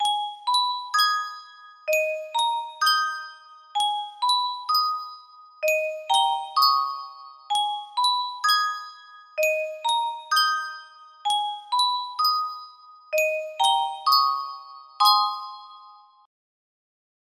Yunsheng Music Box - Tchaikovsky The Sick Doll 5363 music box melody
Full range 60